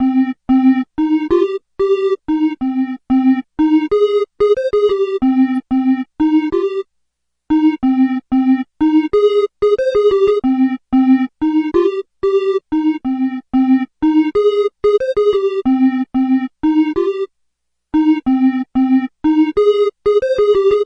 乙烯基钢琴循环播放 92 Bpm
描述：用Bmin9 Gmaj9演奏
标签： 92 bpm Hip Hop Loops Piano Loops 898.95 KB wav Key : B
声道立体声